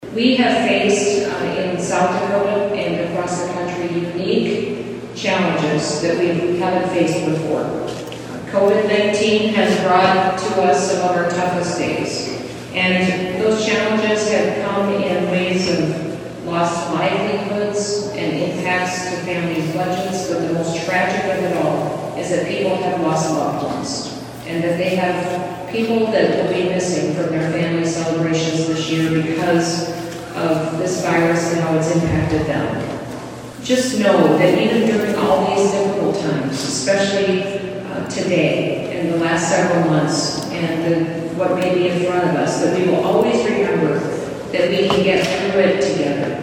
The Christmas at the Capitol holiday display in Pierre kicked off with the Grand Tree Lightning Ceremony in the Capitol Rotunda last night (Tues.).